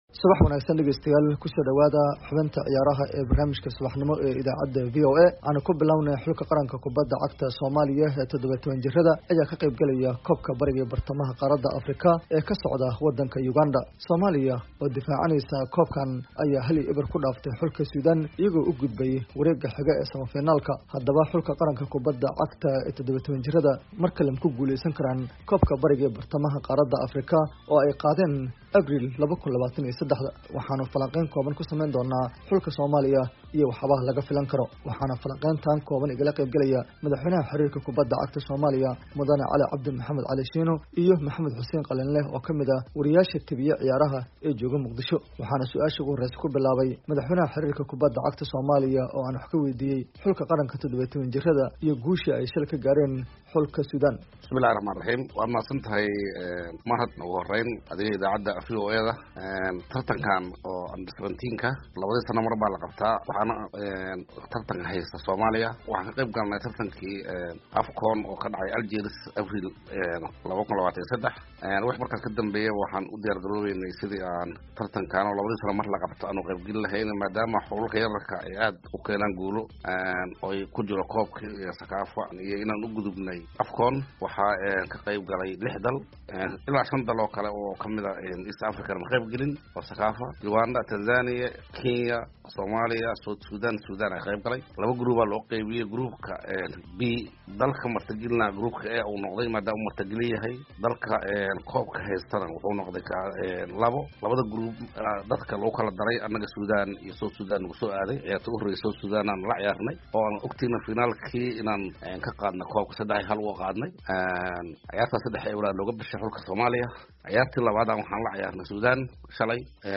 Warbixintaan waxaa Muqdisho kasoo diray